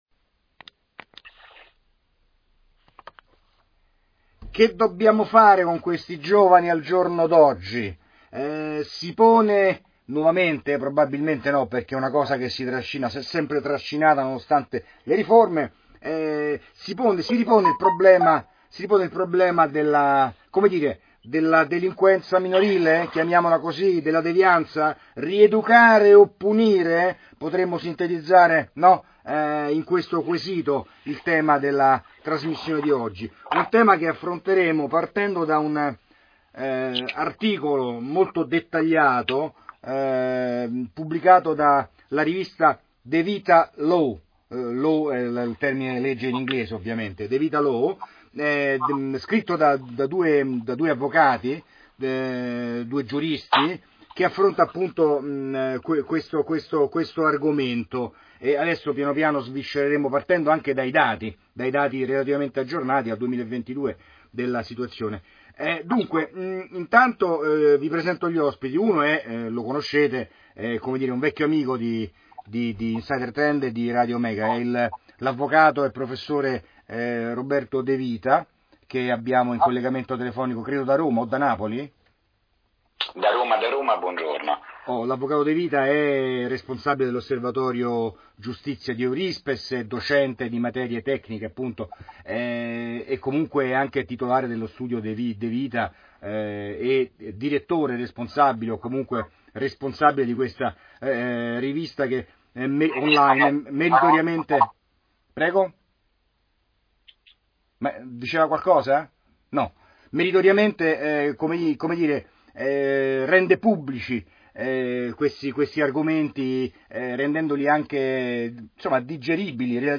Enregistrement audio intégral de l’audience